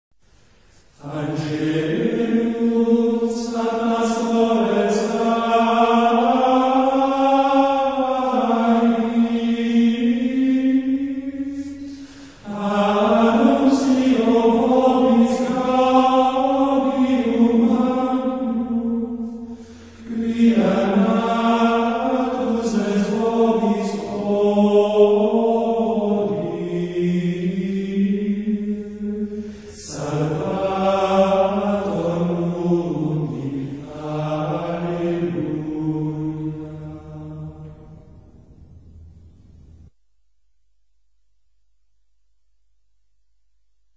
Григорианское пение: сборники